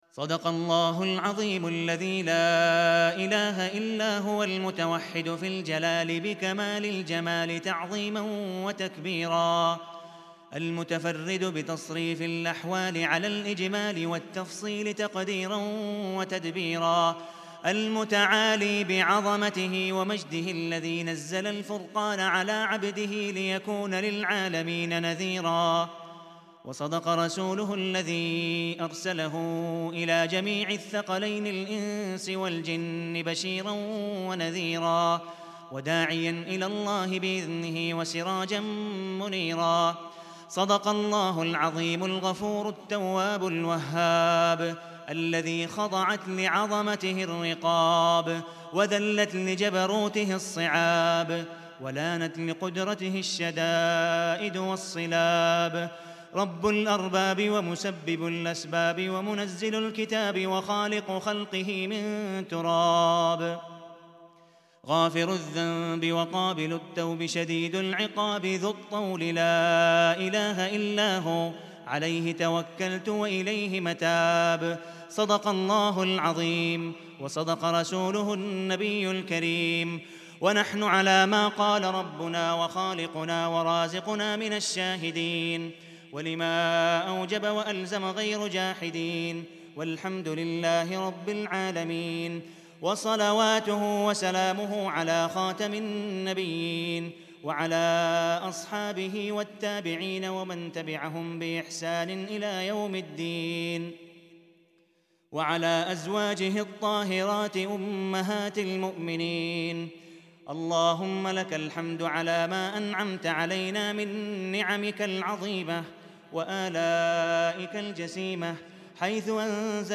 دعاء